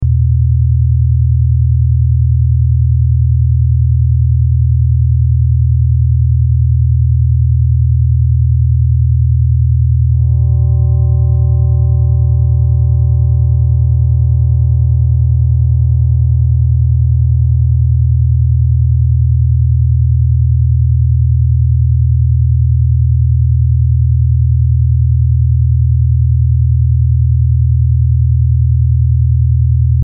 This Mp3 Sound Effect Struggling to think straight? This brown noise helped me shut out the fog.